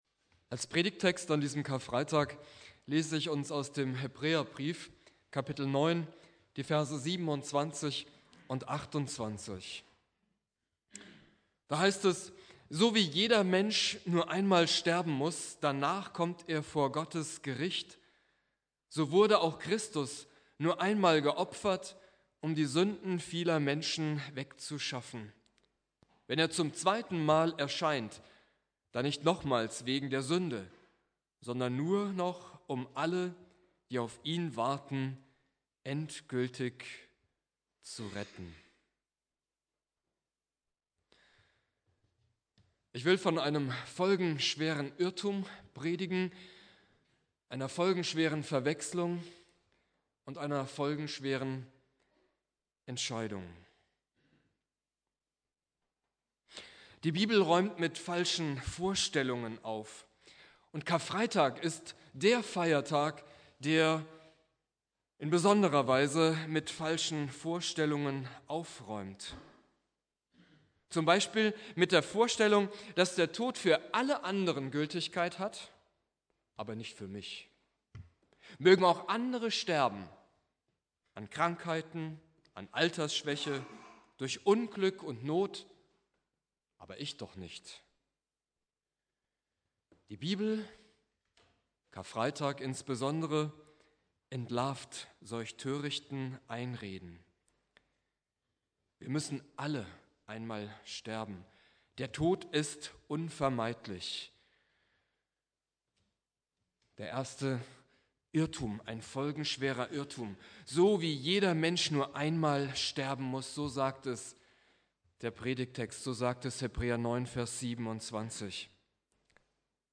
Predigt
Karfreitag Prediger